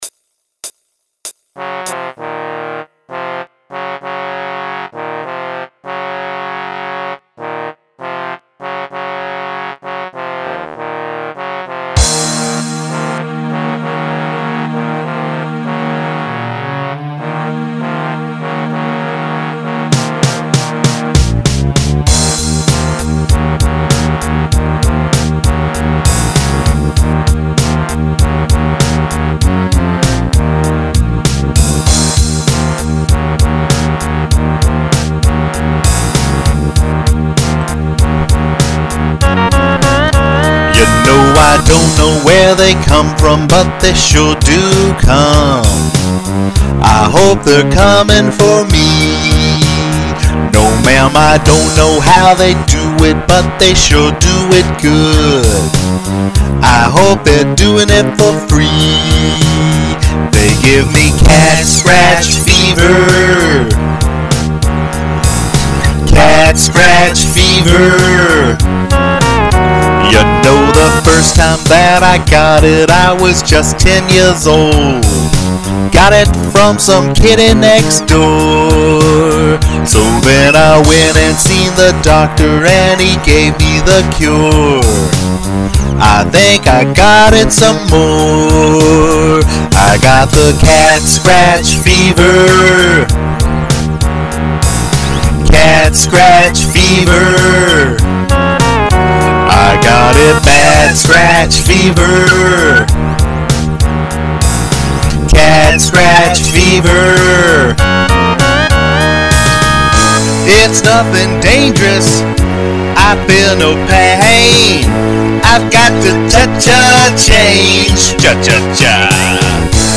• Instruments played - None
• My daughter's instruments - Oboe